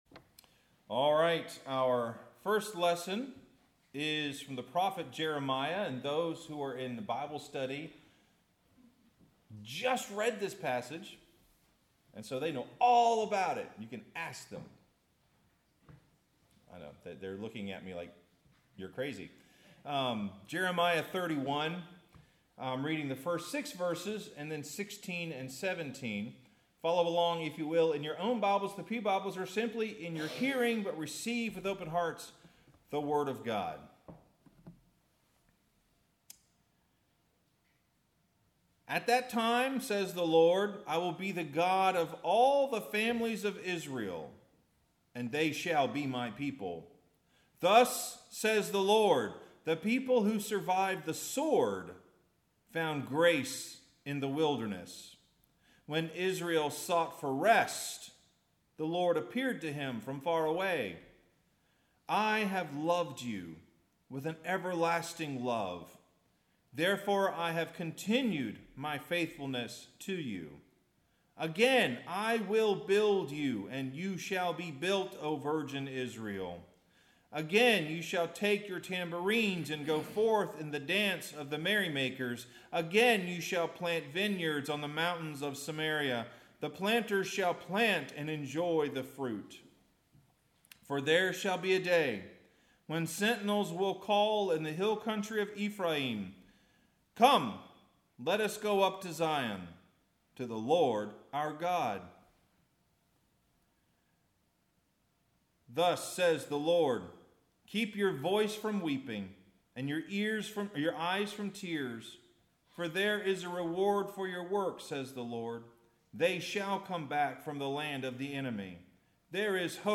Sermon – We Know Where We Are Going